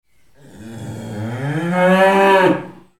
دانلود صدای گاو نر و بزرگ برای ساخت کلیپ و تدوین فیلم از ساعد نیوز با لینک مستقیم و کیفیت بالا
جلوه های صوتی